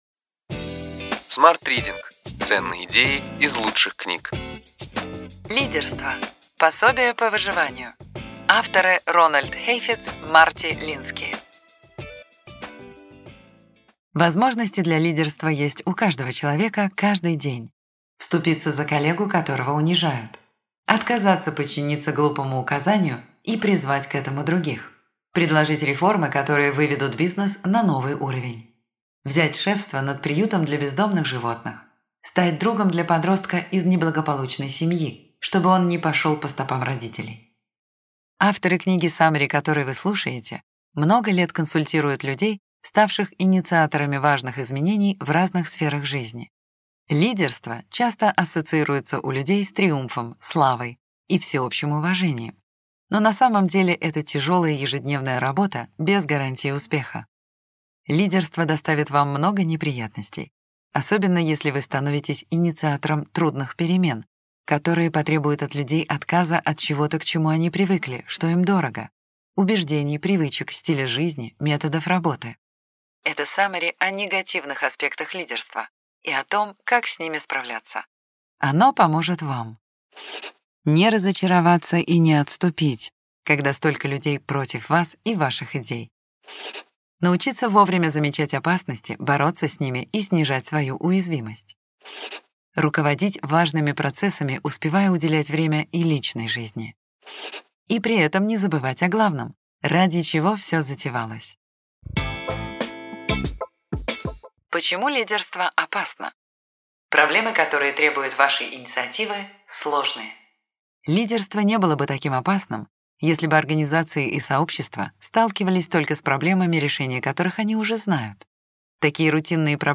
Аудиокнига Ключевые идеи книги: Лидерство: пособие по выживанию. Марти Лински, Рональд Хейфец | Библиотека аудиокниг